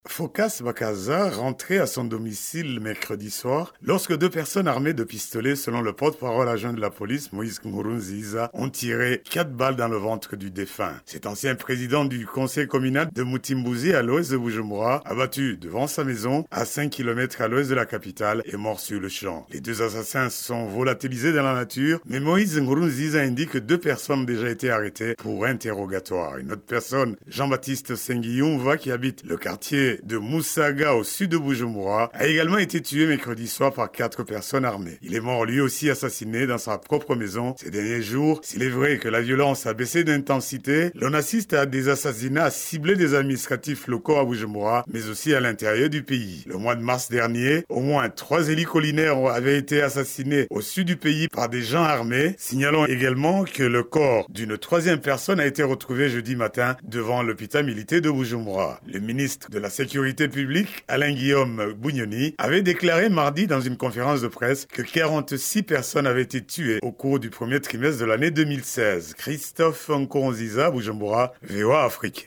Brèves Sonores